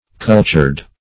Cultured \Cul"tured\ (k?l"t?rd), a.